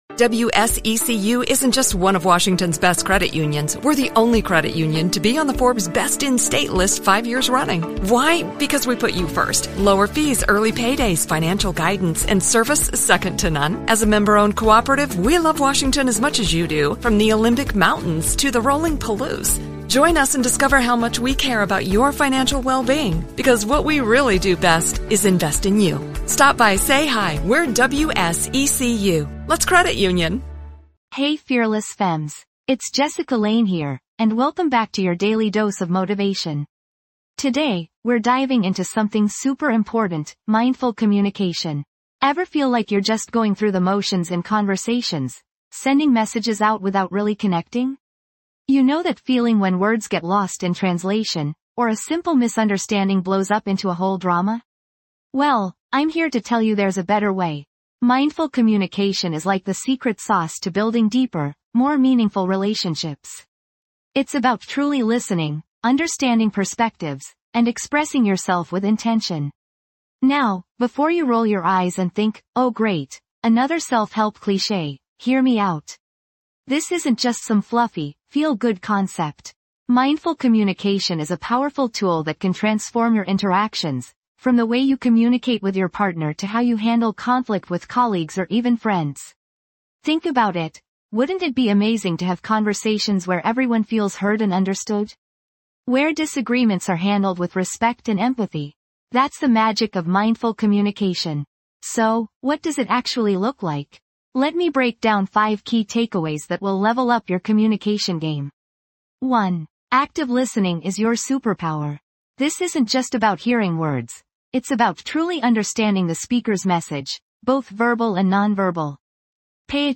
Podcast Category:. Self-Improvement, Personal Development, Relationships & Family, Inspirational Talks
This podcast is created with the help of advanced AI to deliver thoughtful affirmations and positive messages just for you.